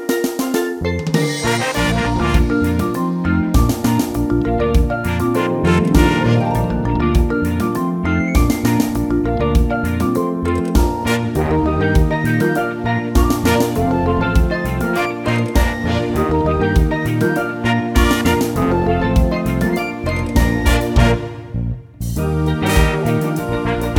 no Backing Vocals Soundtracks 3:14 Buy £1.50